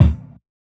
KICKCLASSICHAV.wav